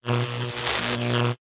emp-electric-2.ogg